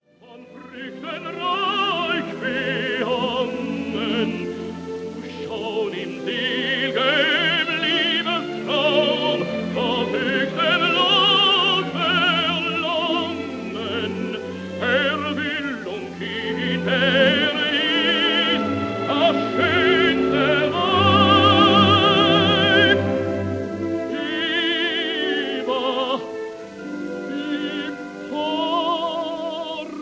This is a 1931 recording of
conductor